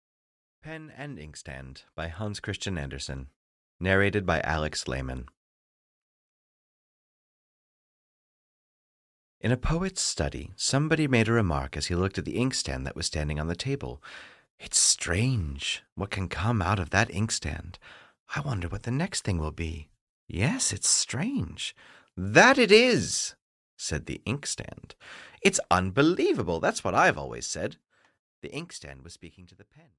Pen and Inkstand (EN) audiokniha
Ukázka z knihy